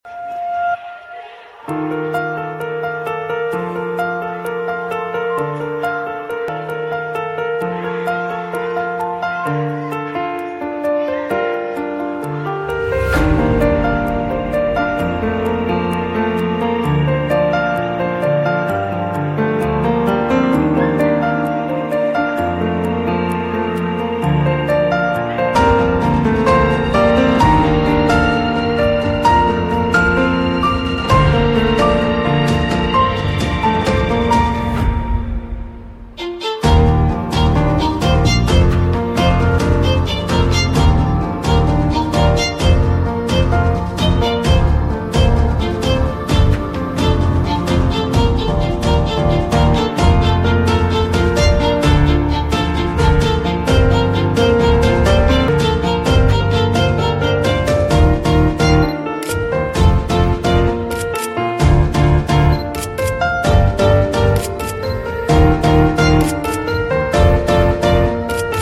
PVC Double Exit Pipe Extrusion sound effects free download